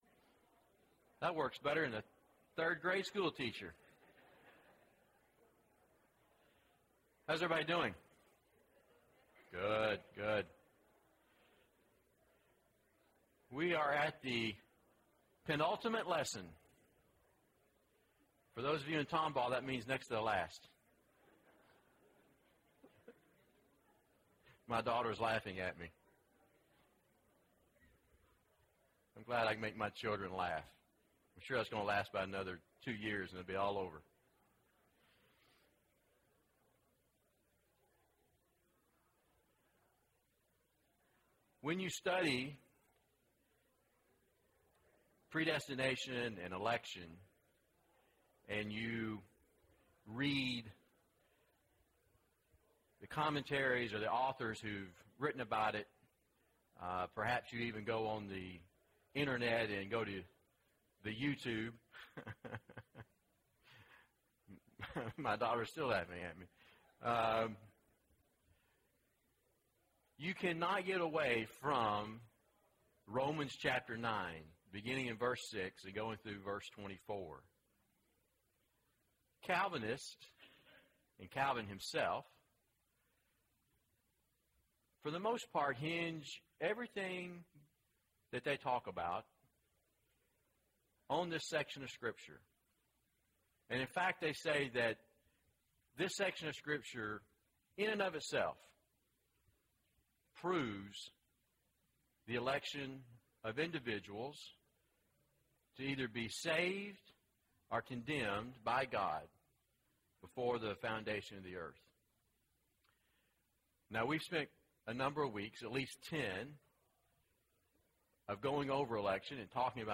The Righteous Live By Faith: Israel (12 of 13) – Bible Lesson Recording